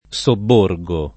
[ S obb 1 r g o ]